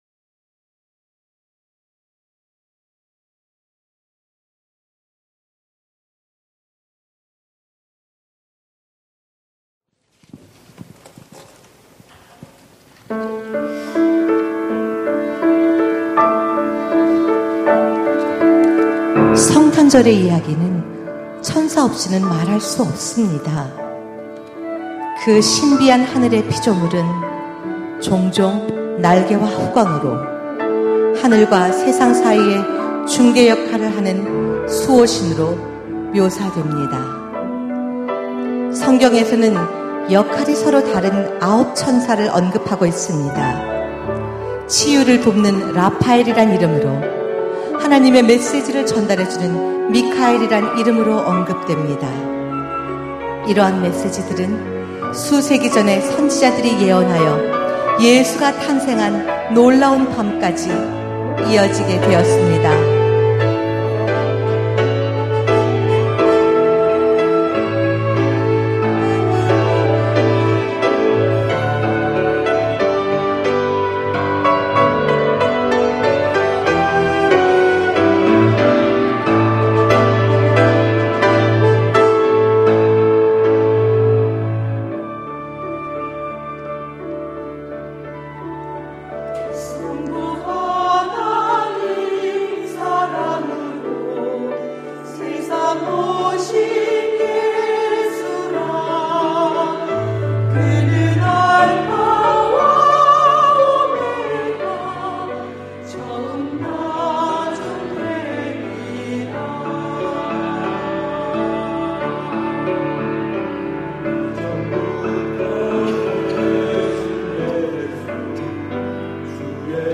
2025 성탄절 칸타타